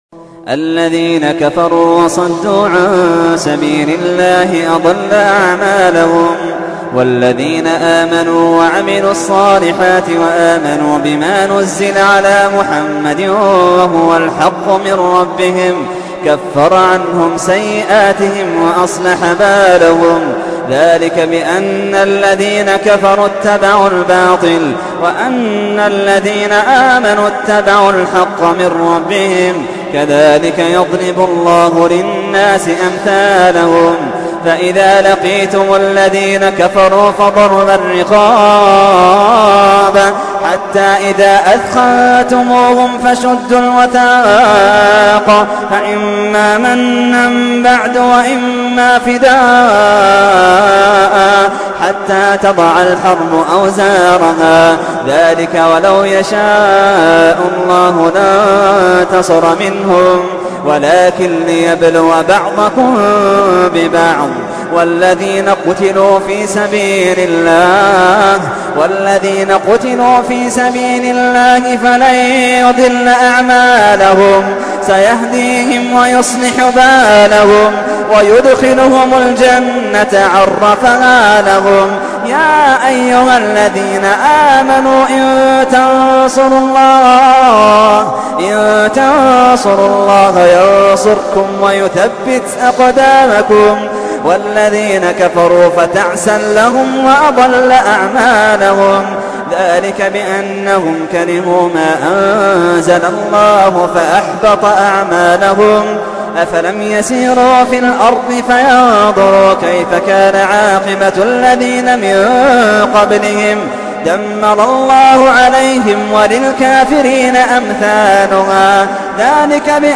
تحميل : 47. سورة محمد / القارئ محمد اللحيدان / القرآن الكريم / موقع يا حسين